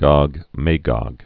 (gŏg; māgŏg)